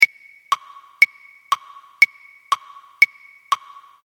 | tictac 02 effect |